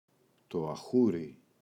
αχούρι, το [a’xuri]